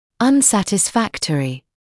[ʌnˌsætɪs’fæktərɪ][анˌсэтис’фэктэри]неудовлетворительный